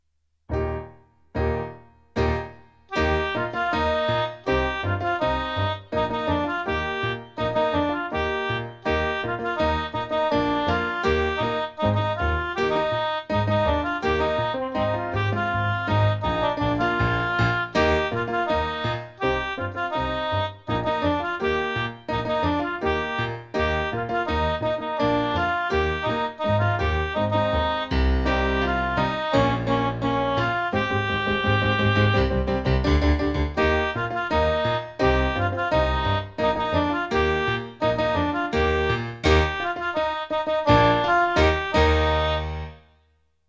Just like in class, every recording will start with three piano chords to get ready before the melody starts and you can sing along (or simply follow along reading the words in the score).  I used a different “instrument” from my keyboard’s sound library for each melody.